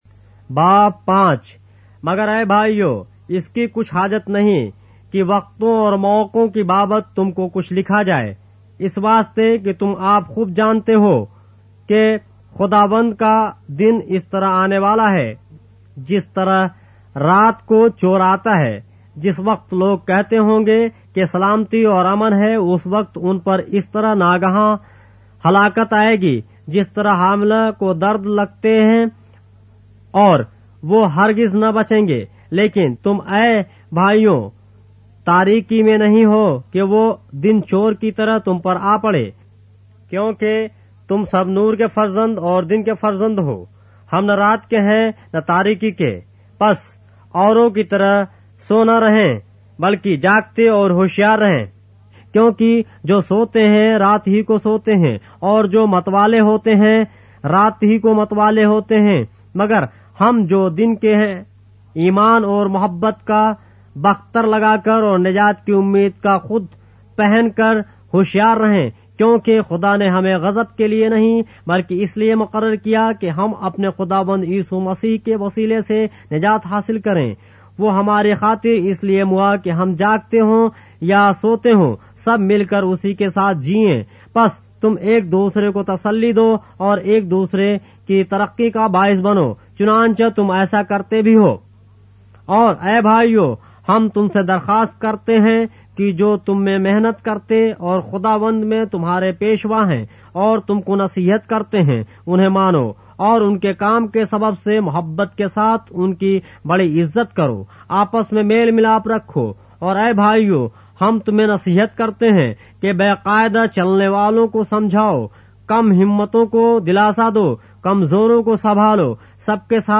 اردو بائبل کے باب - آڈیو روایت کے ساتھ - 1 Thessalonians, chapter 5 of the Holy Bible in Urdu